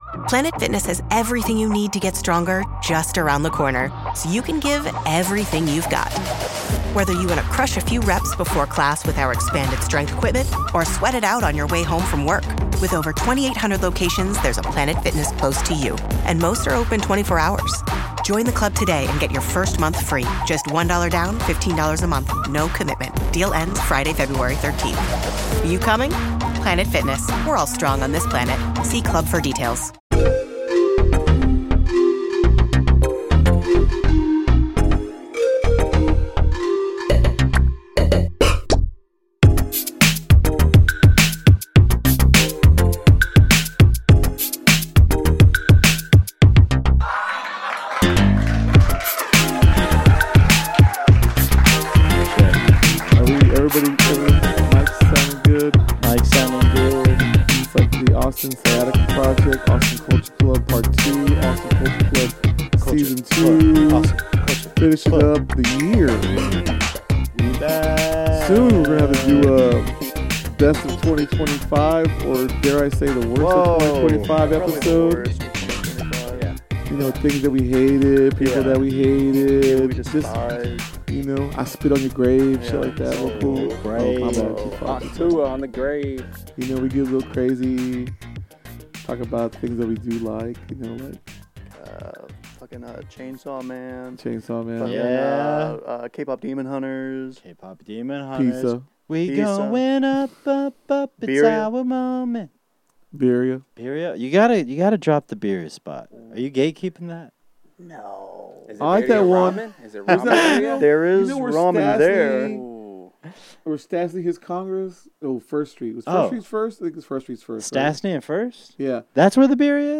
Be a guest on this podcast Language: en Genres: Comedy , Comedy Interviews , Society & Culture Contact email: Get it Feed URL: Get it iTunes ID: Get it Get all podcast data Listen Now...